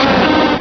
Cri de Cochignon dans Pokémon Rubis et Saphir.